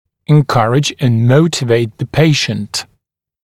[ɪn’kʌrɪʤ ənd ‘məutɪveɪt ðə ‘peɪʃ(ə)nt] [en-][ин’каридж энд ‘моутивэйт зэ ‘пэйш(э)нт] [эн-]побуждать к сотрудничеству и мотивировать пациента